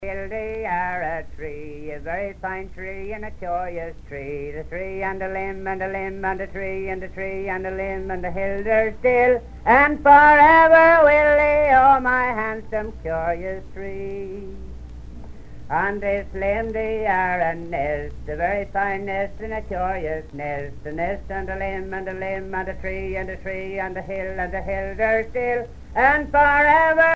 Terre-Neuve
Pièce musicale éditée